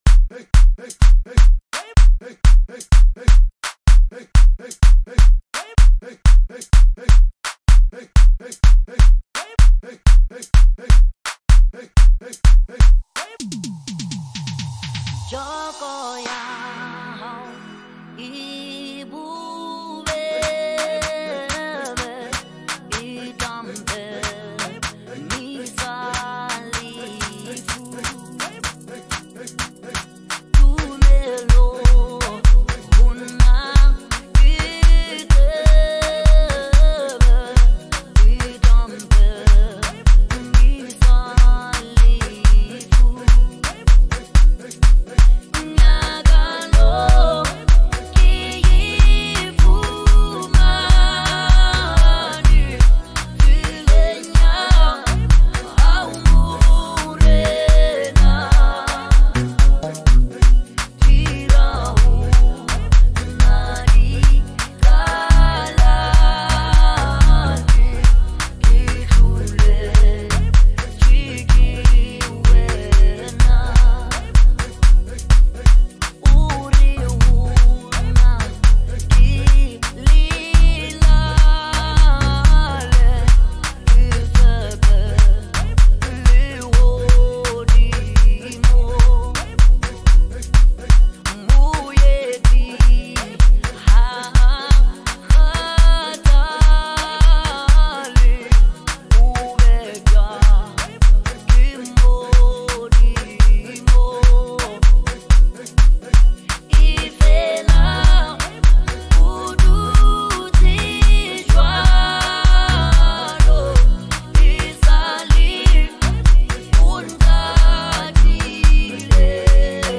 QGOM
moving Gospel House anthem